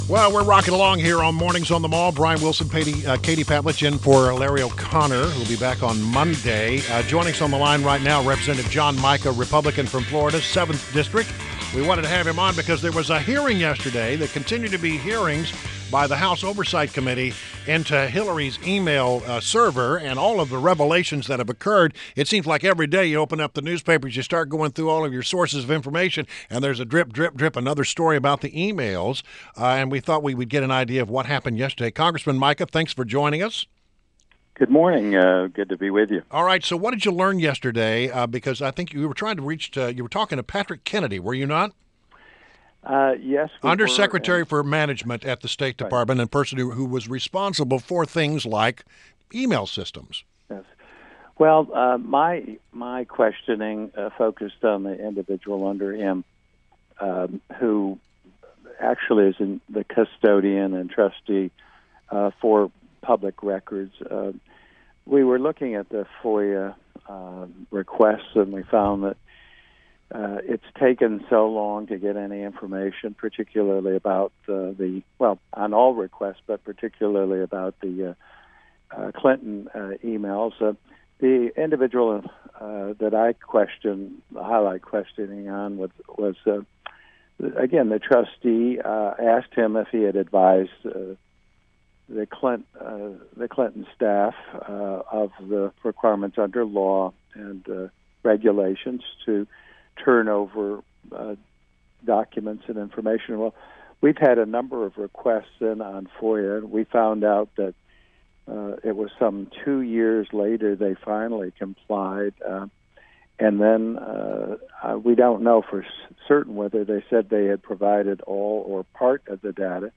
WMAL Interview - REP. JOHN MICA - 09.09.16